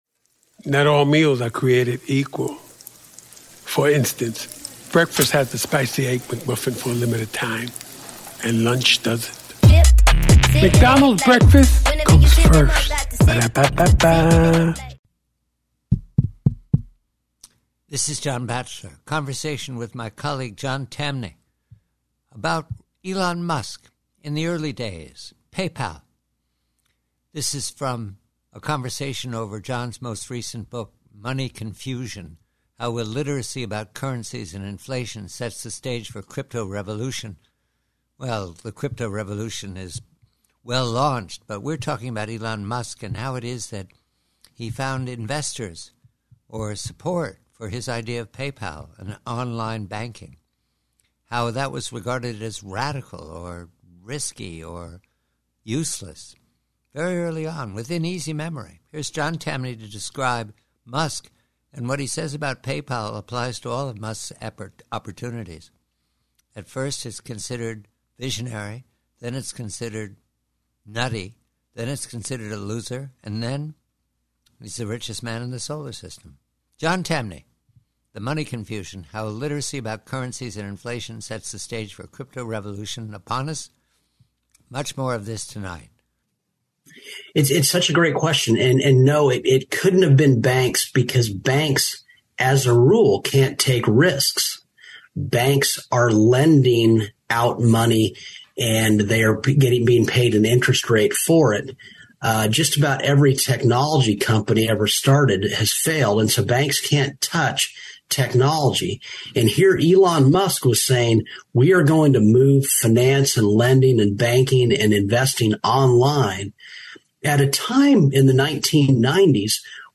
PREVIEW: ELON MUSK: Conversation